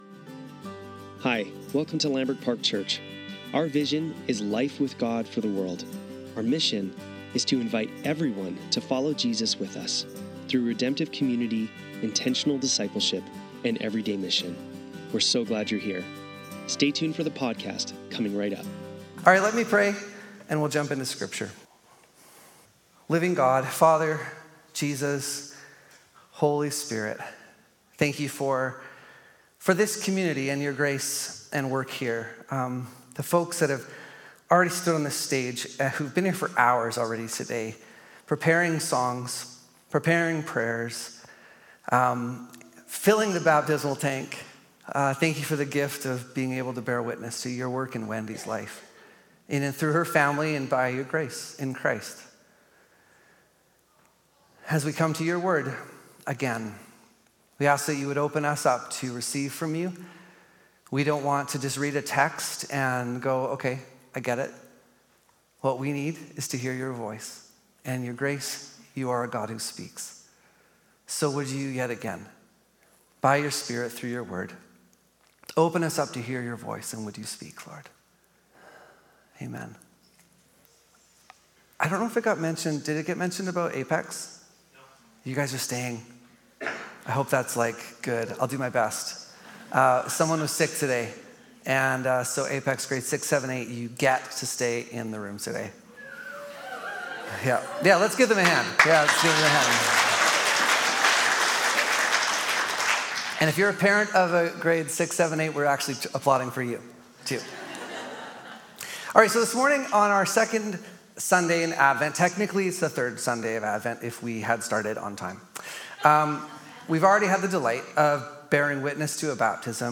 Lambrick Sermons | Lambrick Park Church
Sunday Service - December 15, 2024